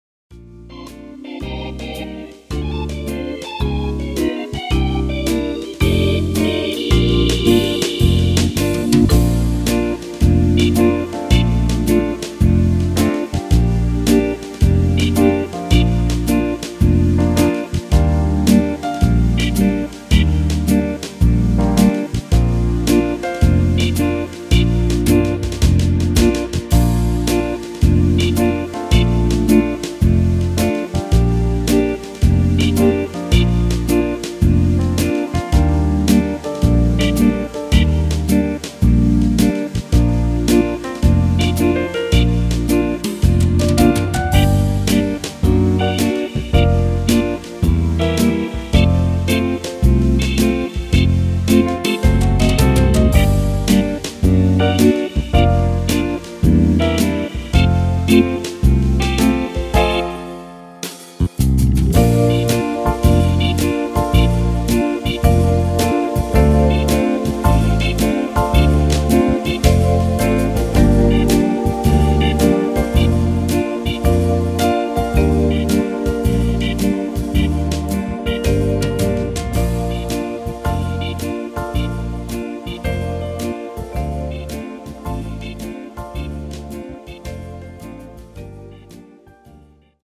Klavier / Streicher